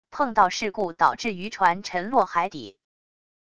碰到事故导致渔船沉落海底wav音频